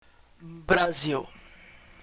Ääntäminen
Vaihtoehtoiset kirjoitusmuodot (vanhentunut) Brazil Ääntäminen BR PT: IPA: /bɾɐ.ˈziɫ/ BR: IPA: /bɾa.ˈziw/ Haettu sana löytyi näillä lähdekielillä: portugali Käännös Ääninäyte Erisnimet 1.